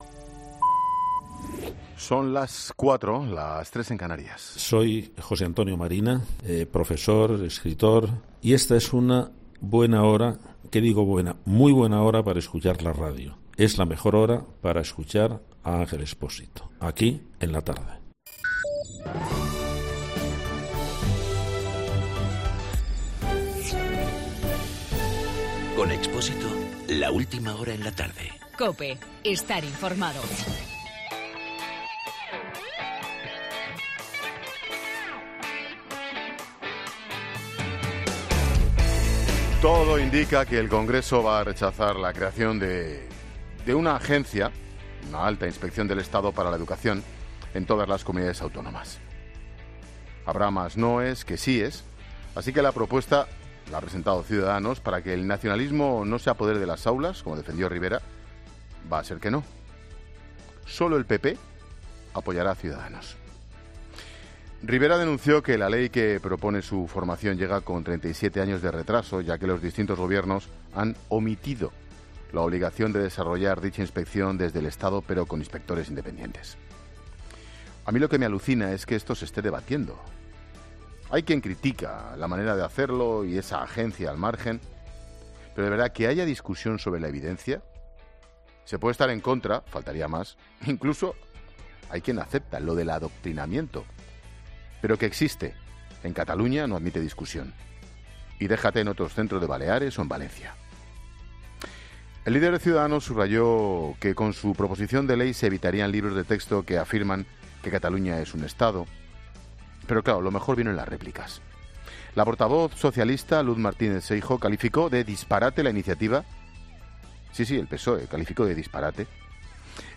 AUDIO: El comentario de Ángel Expósito al rechazarse en el Congreso al estar solo a favor Ciudadanos y PP.
Monólogo de Expósito